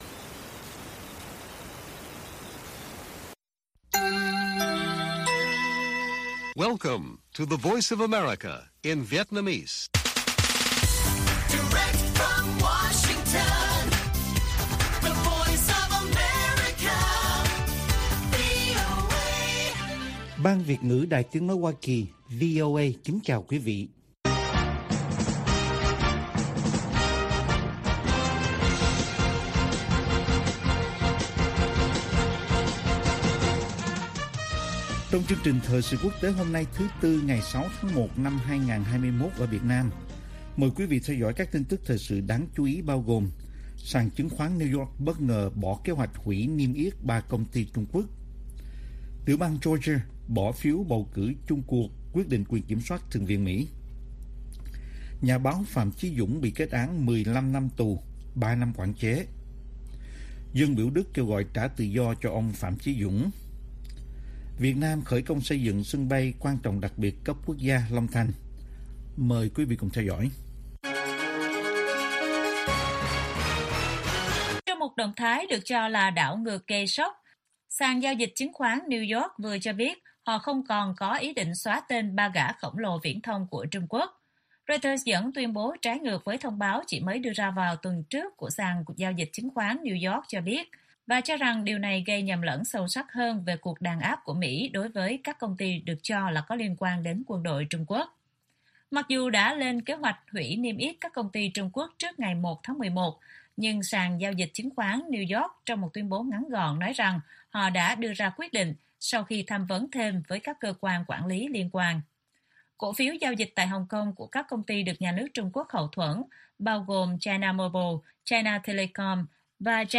Bản tin VOA ngày 6/1/2021